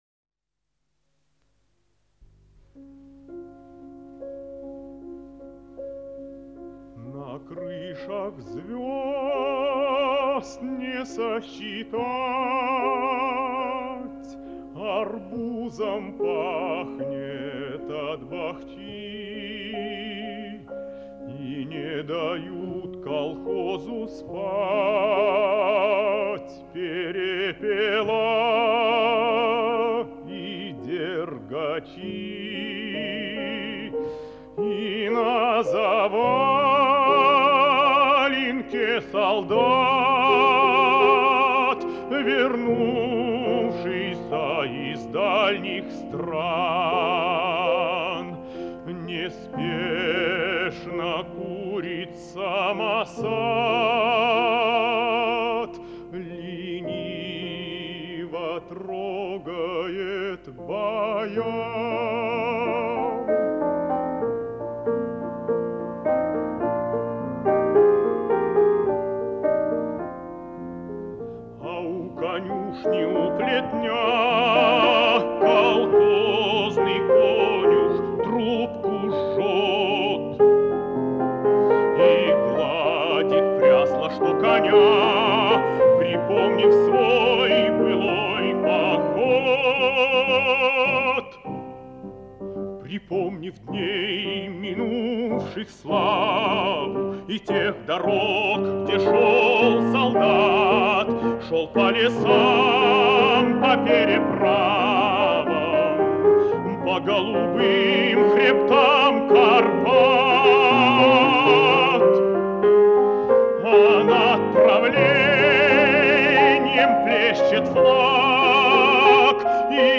Ко дню рождения исполнителя, большого советского певца